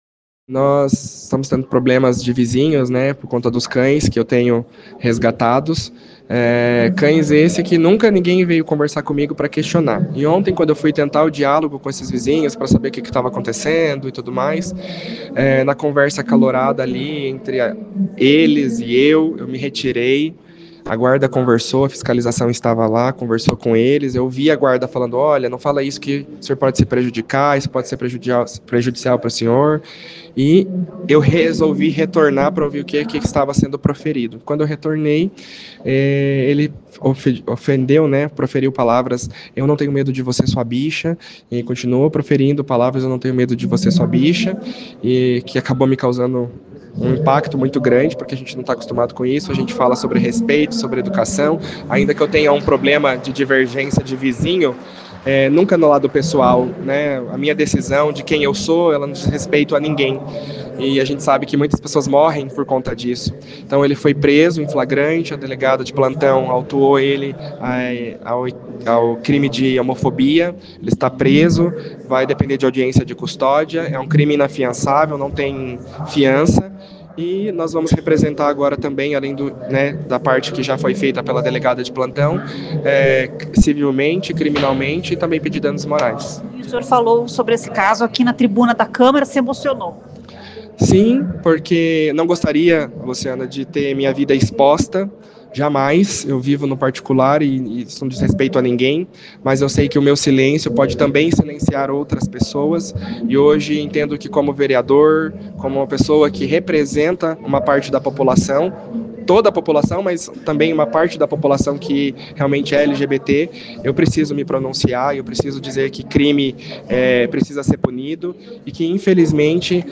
O vereador falou sobre o fato na tribuna da Câmara Municipal na sessão desta terça-feira (9) e chegou a chorar.
Ouça o que diz o vereador: